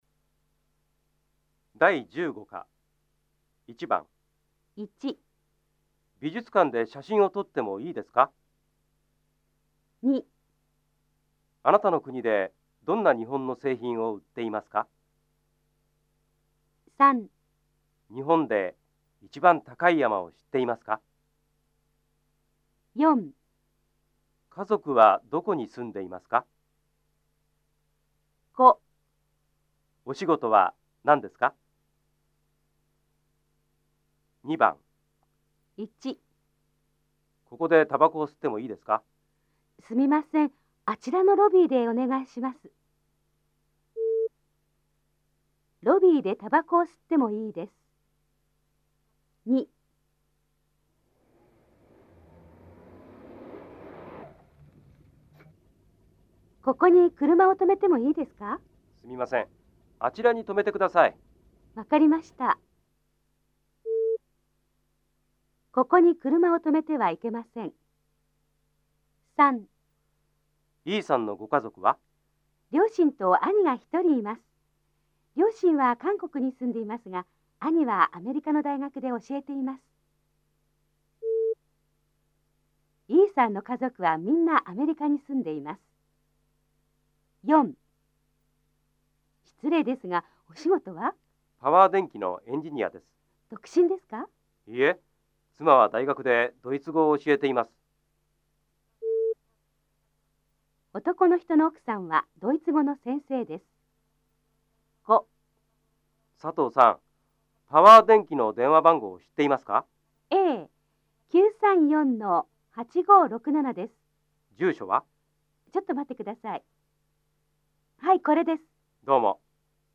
大家的日语-第15课听力练习